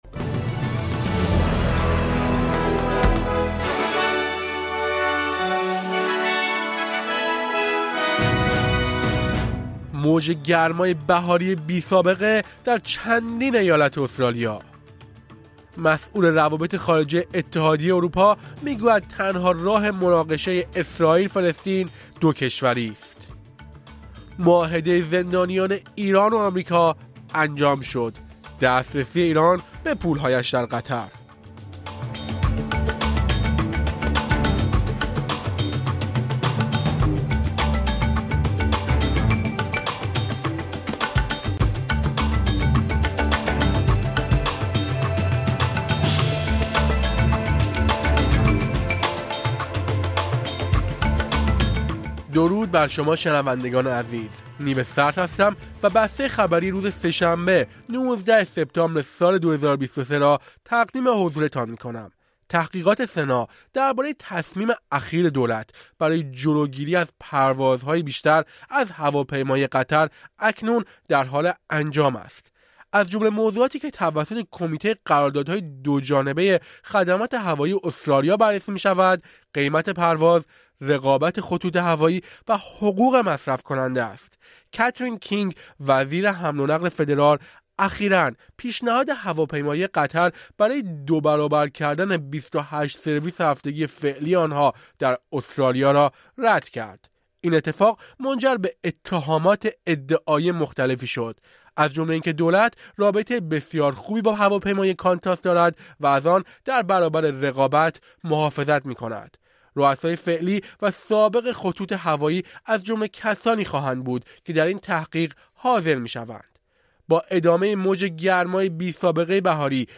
در این پادکست خبری مهمترین اخبار استرالیا، جهان و ایران در یک هفته منتهی به سه‌شنبه ‍۱۹ سپتامبر، ۲۰۲۳ ارائه شده است.